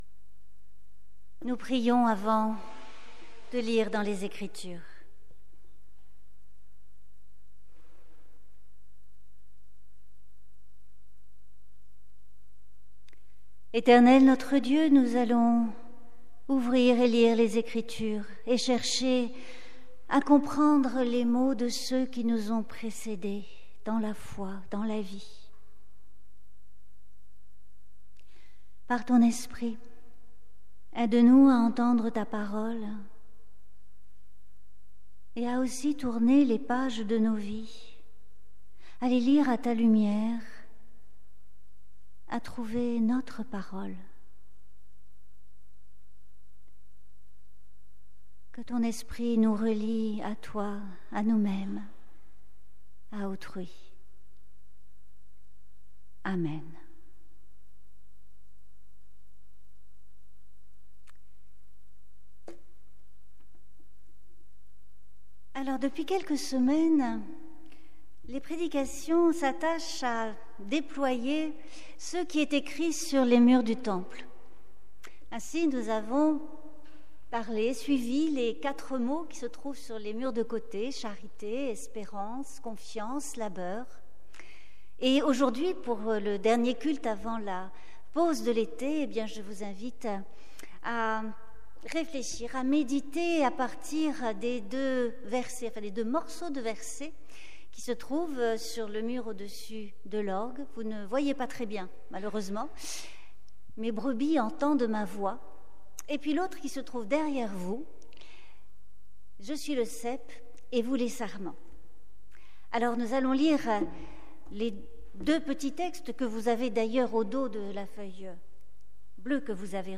Conférence : Arrachement, intégration assimilation ? Lectures croisées du Livre de Ruth